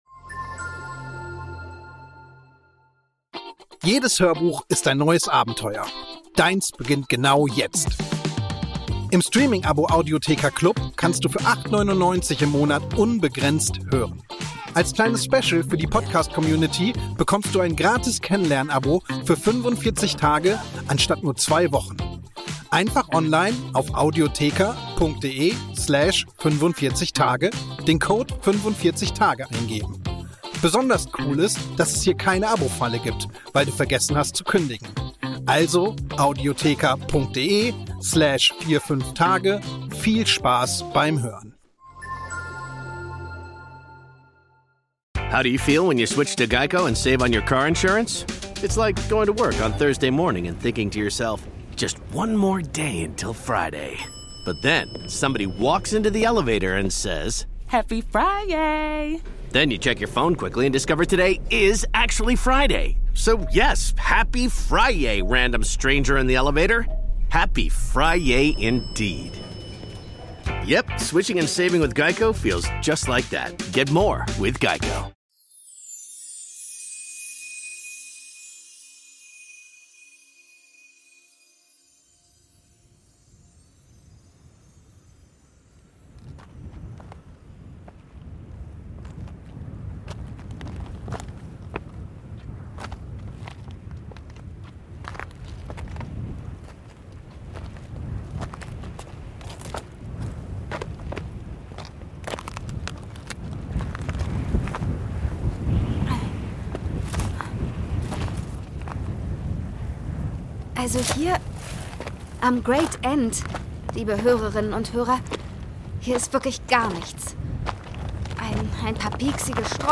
11. Türchen | Die Aurorin Absolventin - Eberkopf Adventskalender ~ Geschichten aus dem Eberkopf - Ein Harry Potter Hörspiel-Podcast Podcast